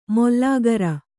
♪ mollāgara